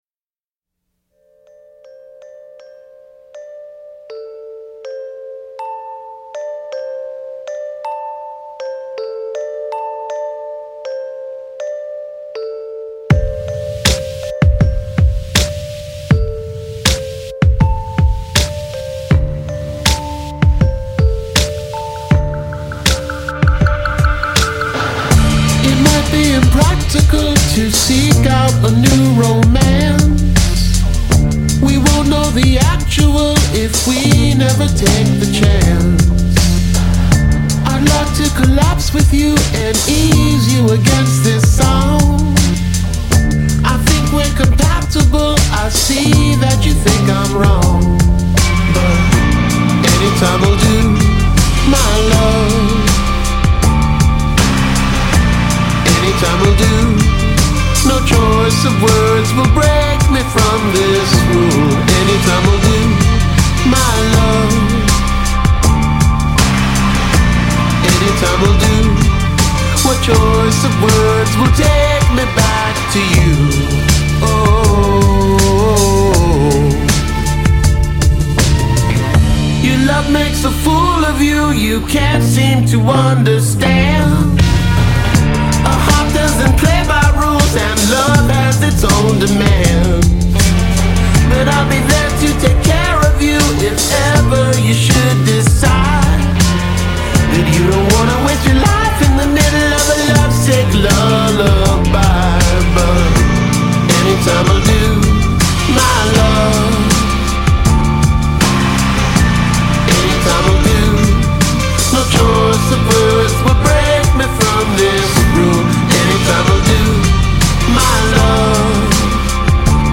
Indie Rock, MP3